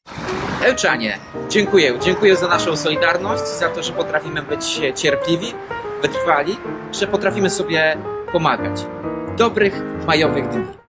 –  to fragment filmu, który powstał dzięki kreatywności i zaangażowaniu ełczan.
Efekt końcowy to kolaż nagrań, które trafiły na skrzynkę Ełckiego Centrum Kultury.